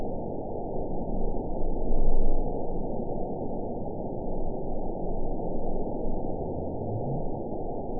event 911293 date 02/21/22 time 15:32:23 GMT (3 years, 3 months ago) score 9.29 location TSS-AB01 detected by nrw target species NRW annotations +NRW Spectrogram: Frequency (kHz) vs. Time (s) audio not available .wav